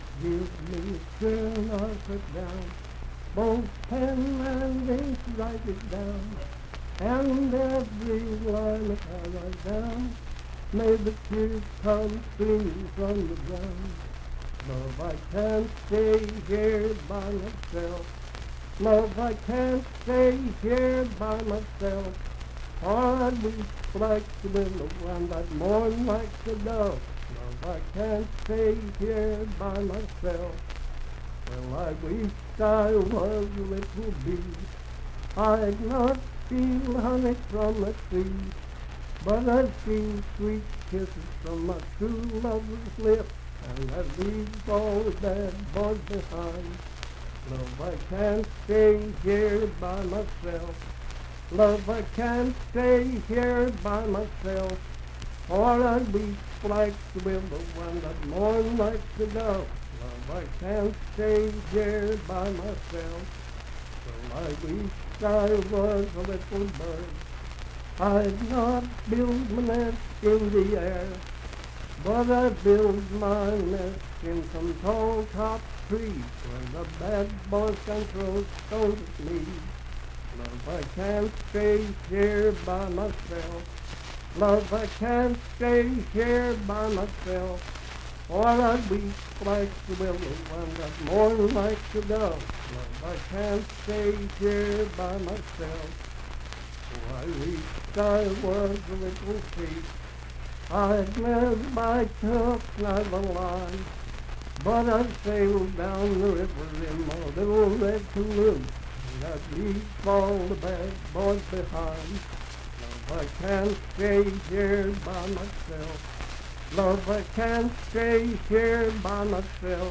Unaccompanied vocal music
in Mount Storm, W.V.
Voice (sung)